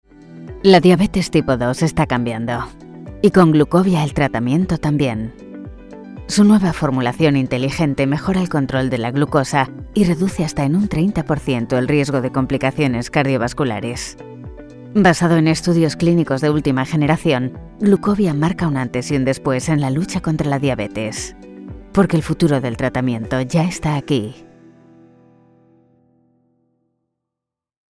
Medical Narration
Castilian Spanish online voice over artist fluent in English.
Soundproof recording booth ( studiobricks)
Mic Neumann U87 Ai